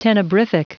Prononciation du mot tenebrific en anglais (fichier audio)